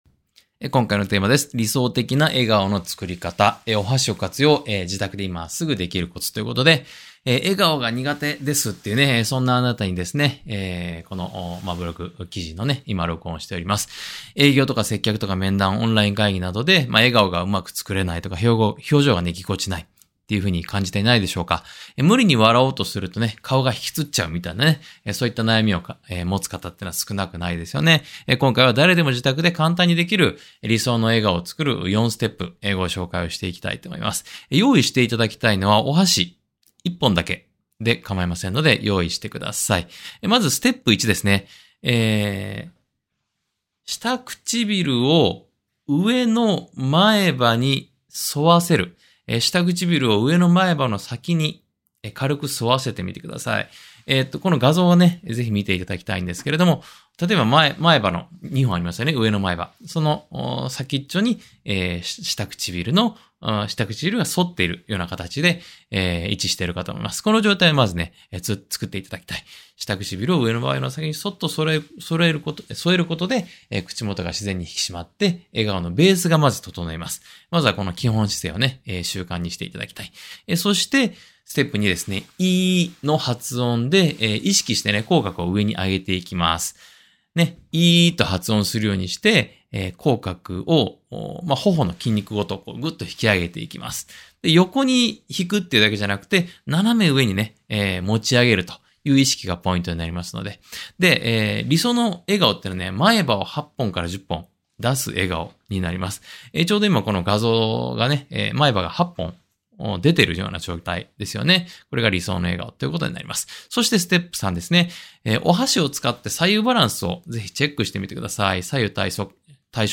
音声回答（︙をクリック→ダウンロード）